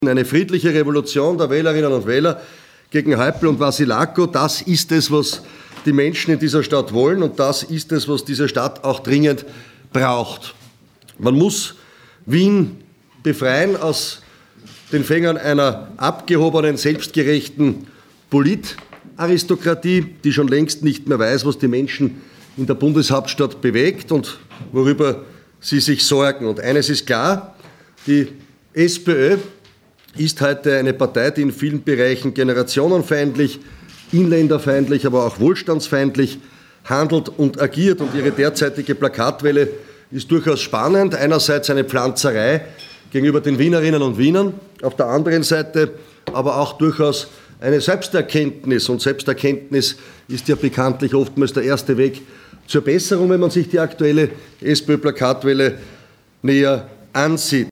O-Töne von HC Strache